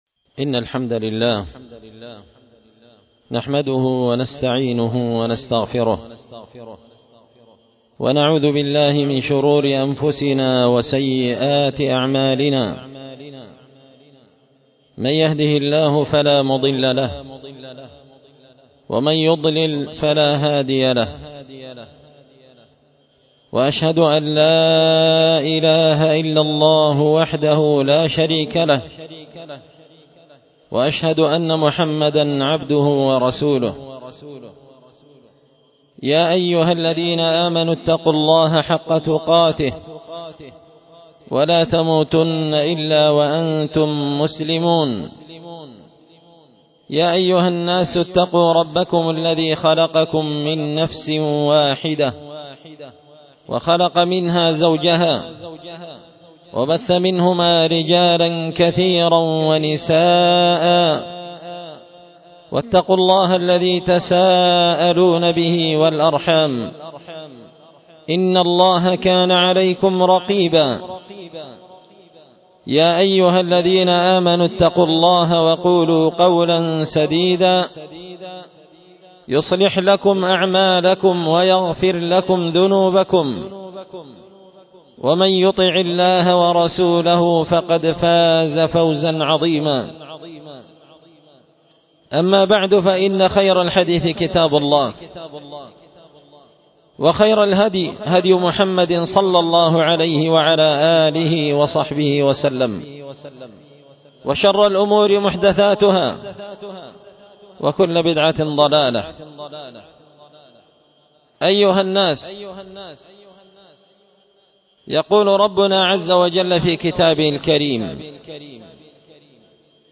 خطبة جمعة بعنوان القول المحكم في مفاسد كرة القدم